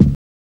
BABY VINYL.wav